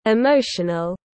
Emotional /ɪˈmoʊʃənl/